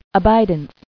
[a·bid·ance]